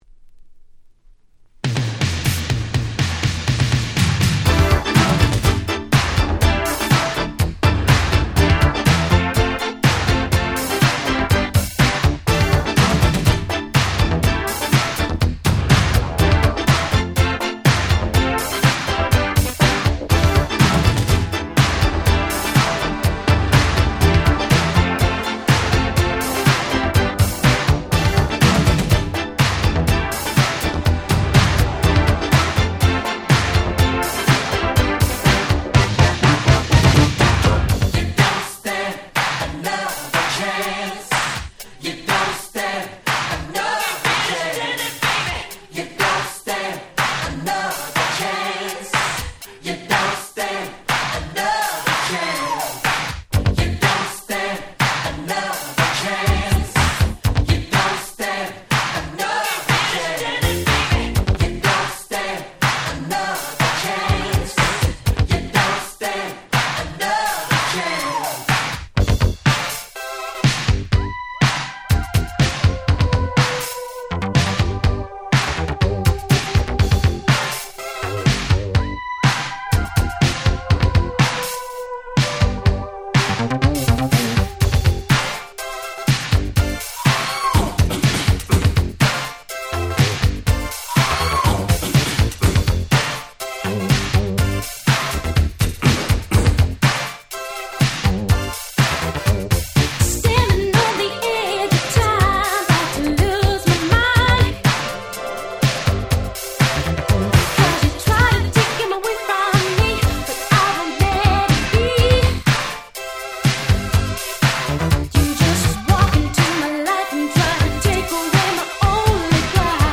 84' Smash Hit R&B / Disco / Funk !!